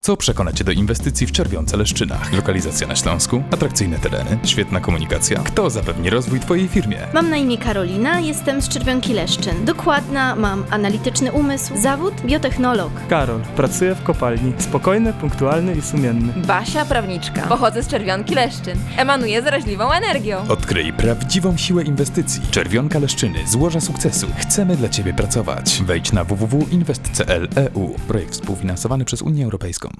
Spot radiowy promujący tereny inwestycyjne Gminy i Miasta Czerwionka-Leszczyny
1167Spot-radiowy-Czerwionka-Leszczyny.wav